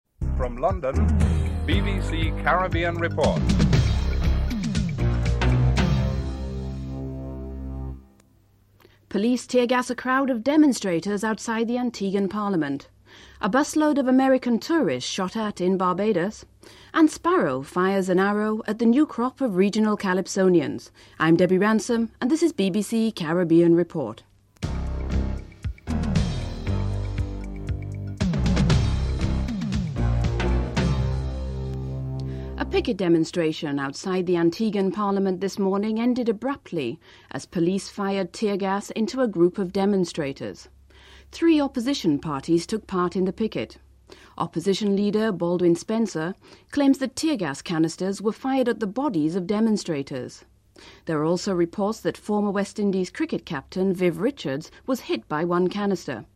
1. Headlines (00:00-00:31)
Clips are played featuring opposing viewpoints from Congressman Charles Rangel and Republican Congressman David Dreier (07:22-08:57)
Jonathan Agnew reports from Australia (12:09-13:56)